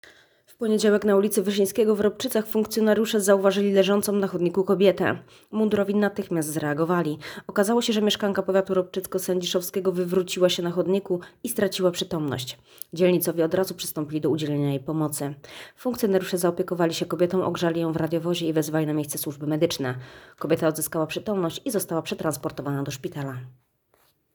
Nagranie audio Sędziszowscy dzielnicowi pomogli kobiecie, która straciła przytomność - mówi mł. asp.